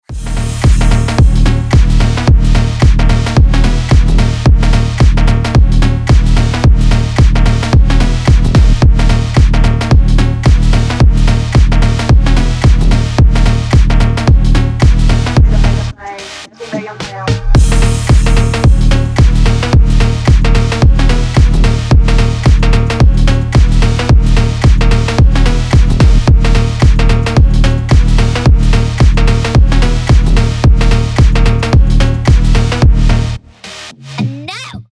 • Качество: 320, Stereo
ритмичные
мощные басы
качающие
детский голос
Стиль: phonk